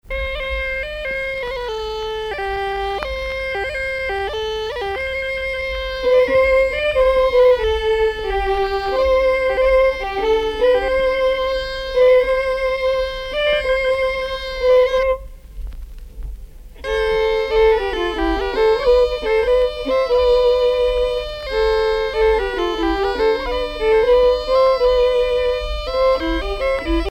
danse : chasse à quatre
Pièce musicale éditée